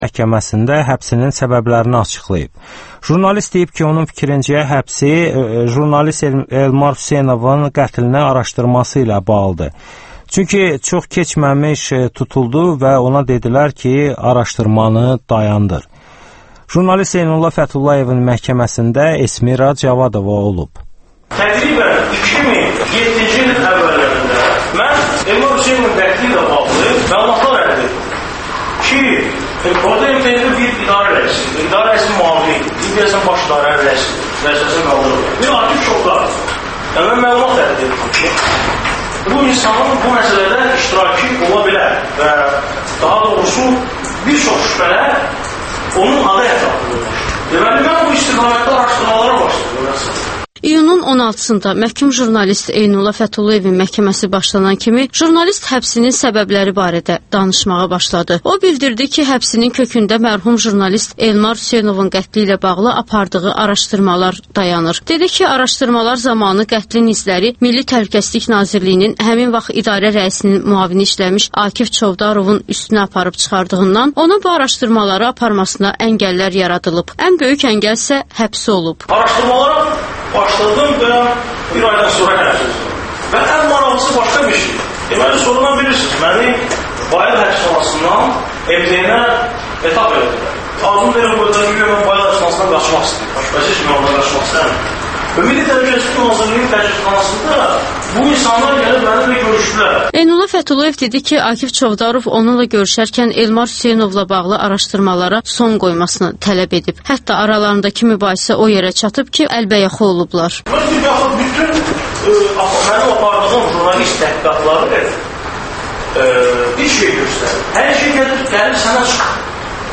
Kontekst - müsahibələr, hadisələrin müzakirəsi, təhlillər